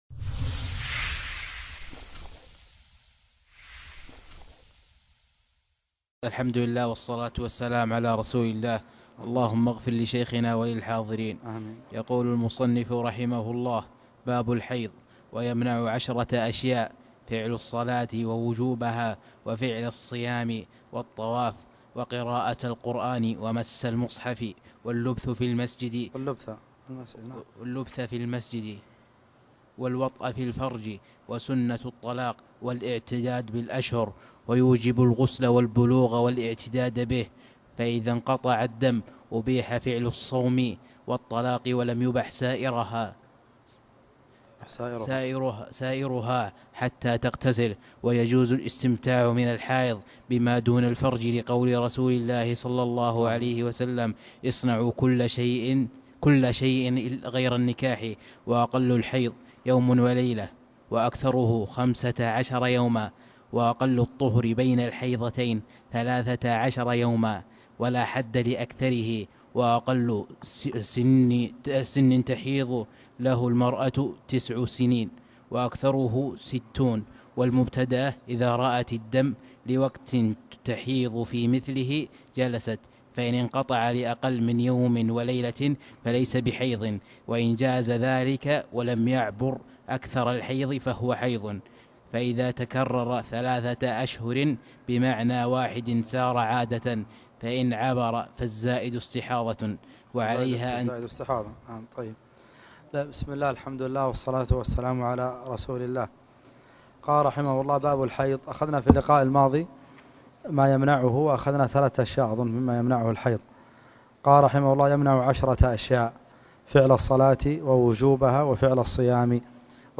الدرس السادس ( شرح عمدة الفقه